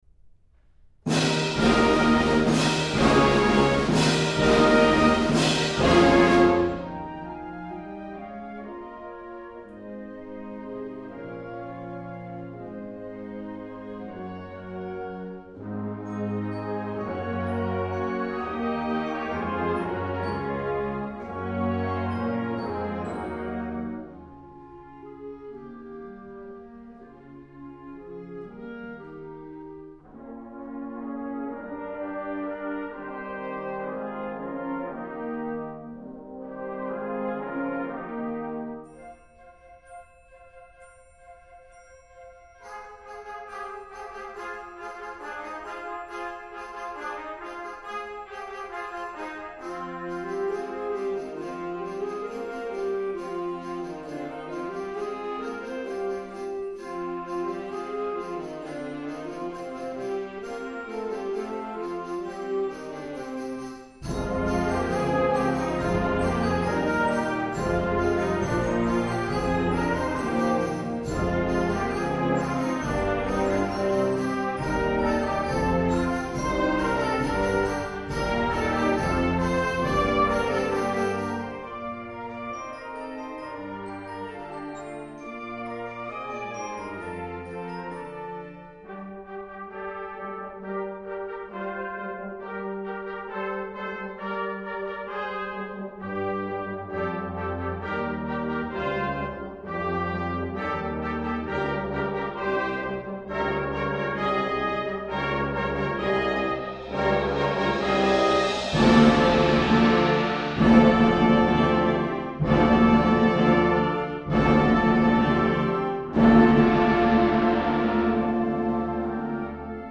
Genre: Band
Flute 1/2
Bb Clarinet 1/2
Alto Saxophone 1/2
Bb Trumpet 1/2
F Horn
Trombone
Tuba
Timpani
Percussion 1 (bells, suspended cymbal)
Percussion 2 (snare drum, tambourine, bass drum)
Percussion 3 (crash cymbals, tam-tam)